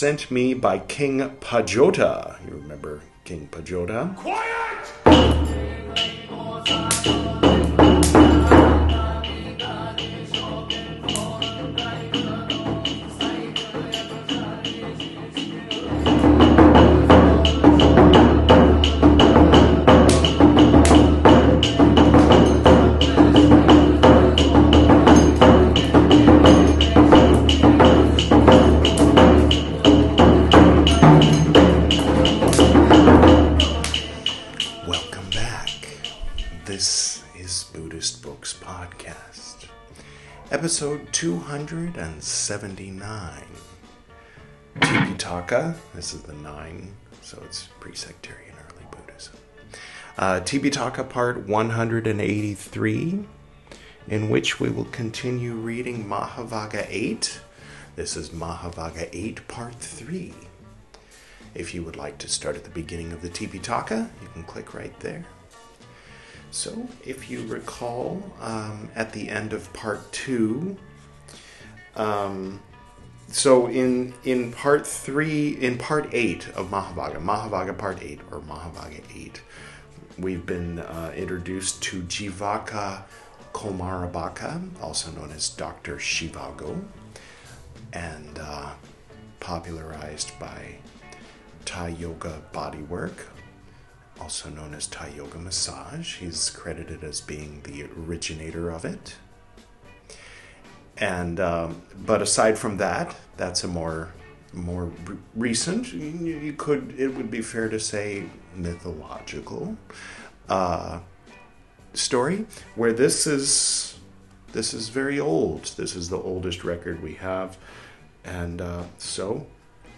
This is Part 183 of my recital of the 'Tipiṭaka,' the 'Three Baskets' of pre-sectarian Buddhism, as translated into English from the original Pali Language. In this episode, we'll continue reading 'Mahāvagga VIII,' from the 'Vinaya Piṭaka,' the first of the three 'Piṭaka,' or 'Baskets.'